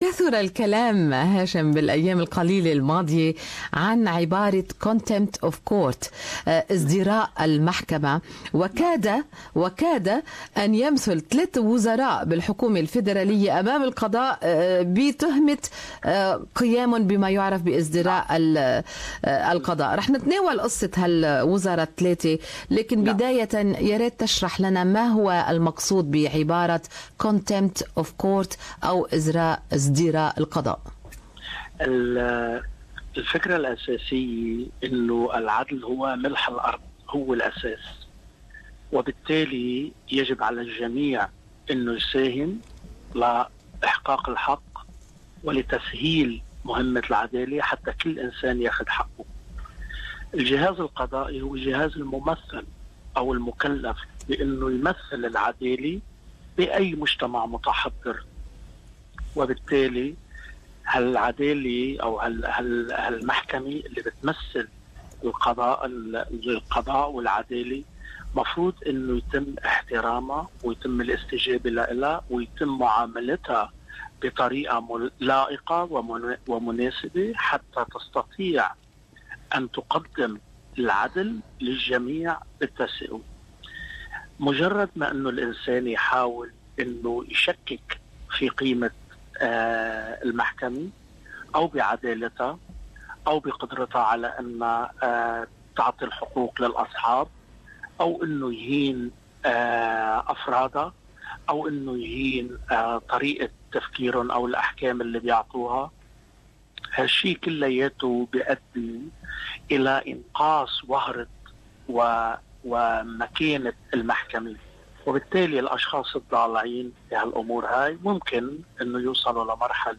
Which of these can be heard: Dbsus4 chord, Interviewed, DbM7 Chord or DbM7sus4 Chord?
Interviewed